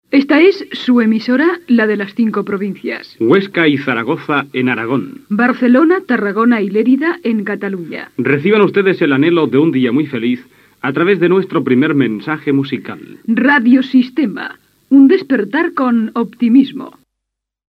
Obertura de l'emissió: La emisora de las 5 provincias - Ràdio Tàrrega, anys 60